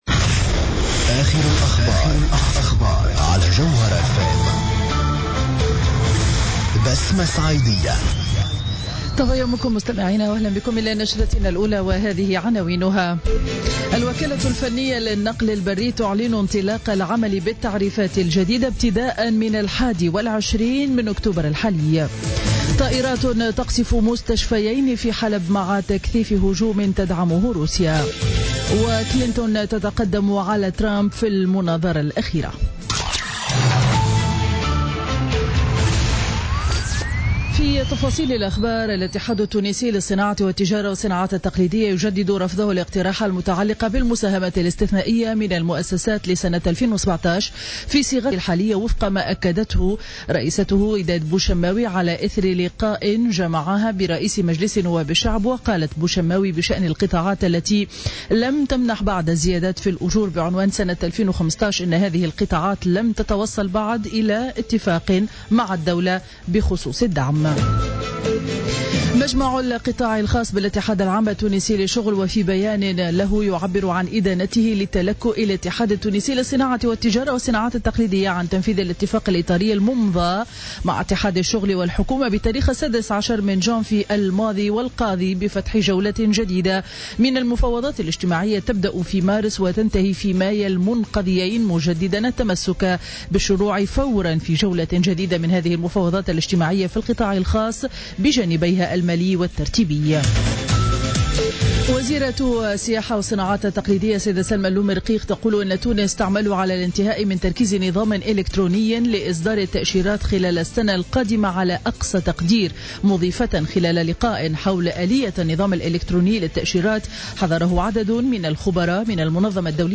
نشرة أخبار السابعة صباحا ليوم الخميس 20 أكتوبر 2016